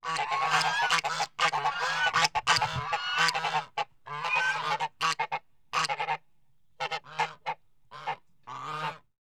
GEESE 1-R.wav